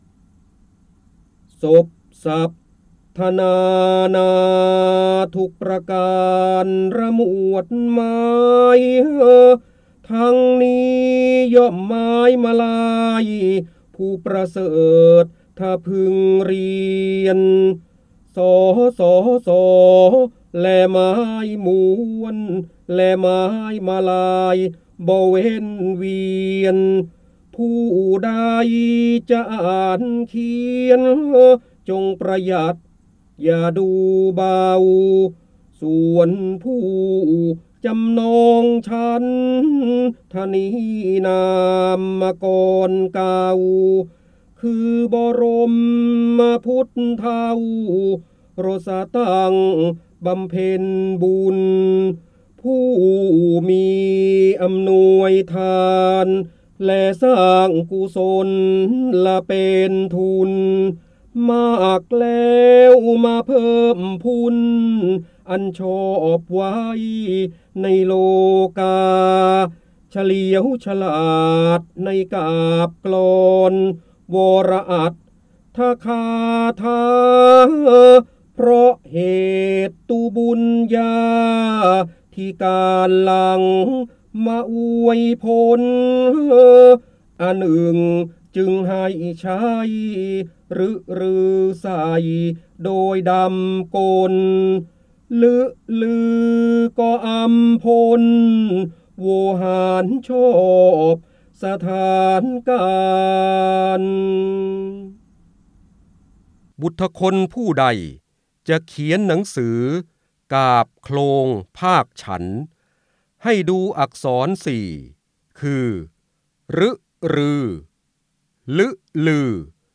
เสียงบรรยายจากหนังสือ จินดามณี (พระโหราธิบดี) บทส่งท้าย
คำสำคัญ : ร้อยกรอง, จินดามณี, พระเจ้าบรมโกศ, ร้อยแก้ว, การอ่านออกเสียง, พระโหราธิบดี